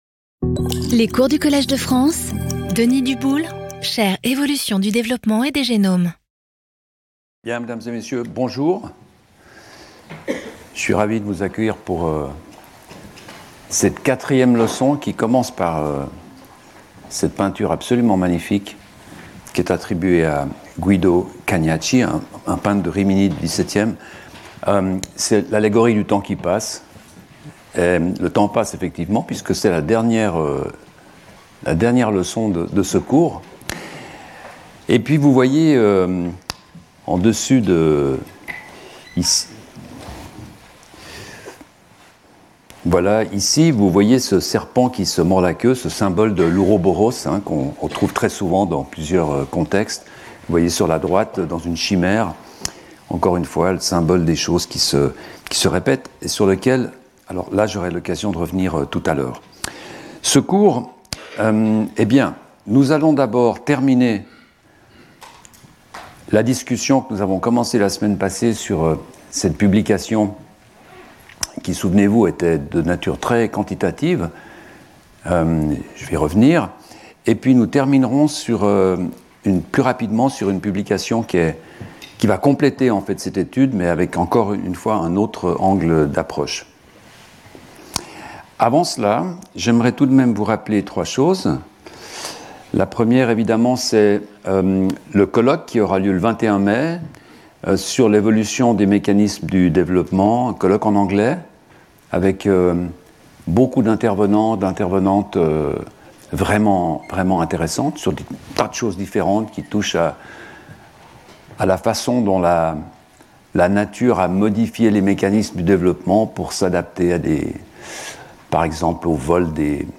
Speaker(s) Denis Duboule Professor at the Collège de France Events Previous Lecture 6 Mar 2026 10:00 to 11:30 Denis Duboule DNA, the proximal or ultimate cause of our evolution ?